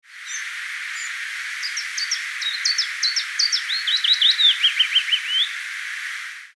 vooral tjiftjaf (alhoewel een tikje vreemd), soms is het